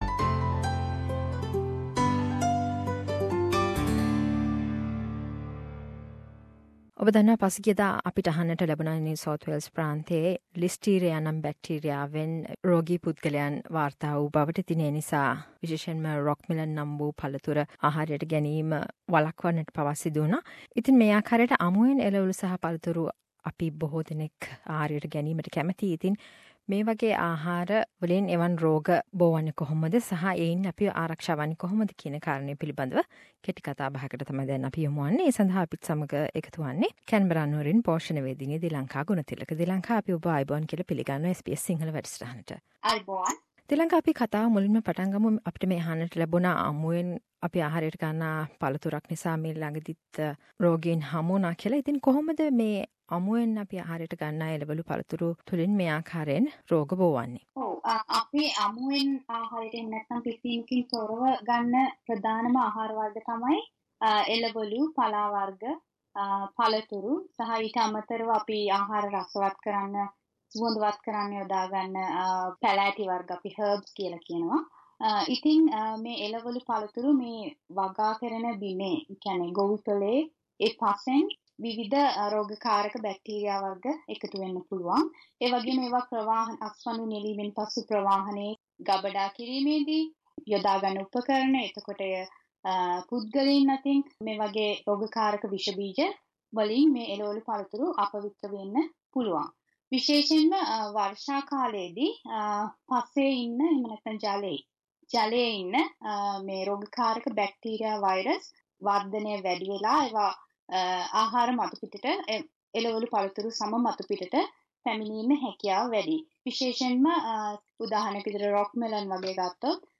A discussion about food safety while eating raw fruits and vegetables